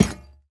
Minecraft Version Minecraft Version latest Latest Release | Latest Snapshot latest / assets / minecraft / sounds / block / vault / place1.ogg Compare With Compare With Latest Release | Latest Snapshot